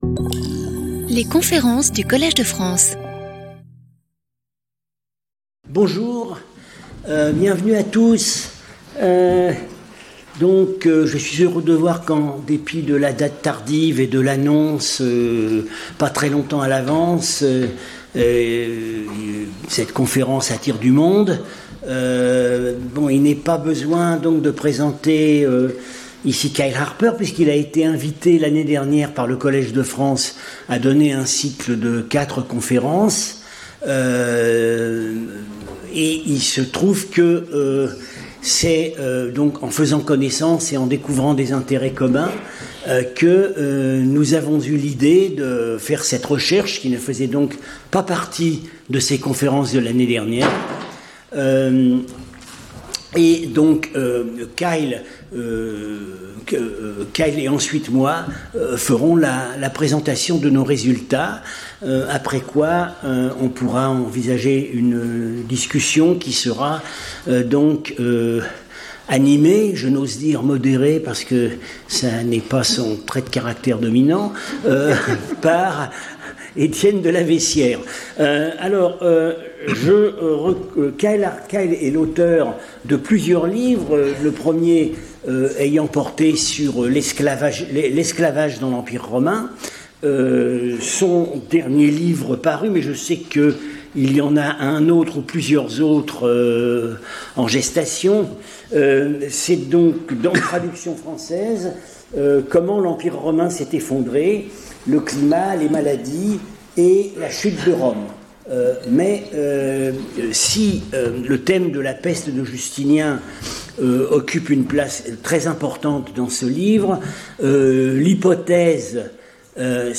Lecture followed by discussion